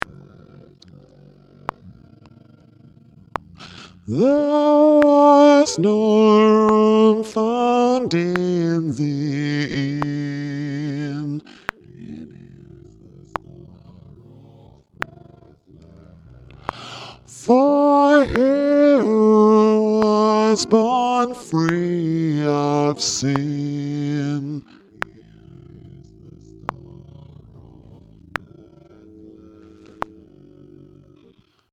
behold-that-star-bass-solo
behold-that-star-bass-solo.mp3